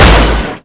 Index of /server/sound/vcmod/collision/heavy